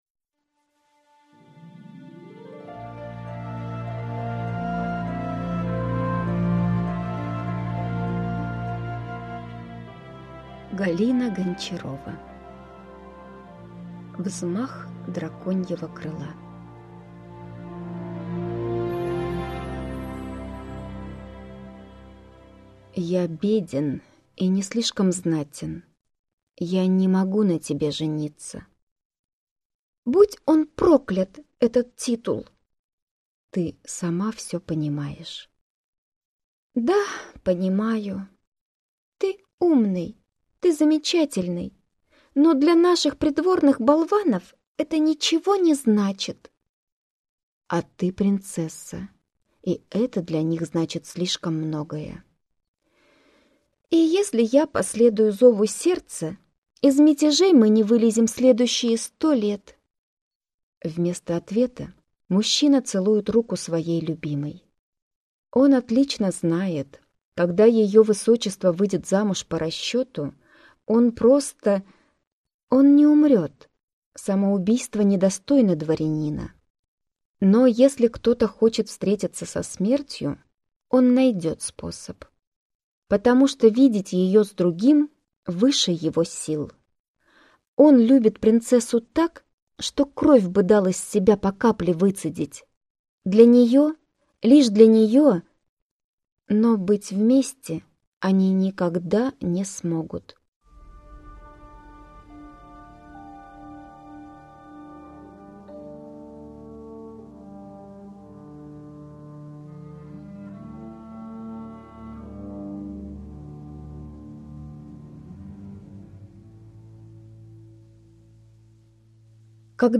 Аудиокнига Взмах драконьего крыла | Библиотека аудиокниг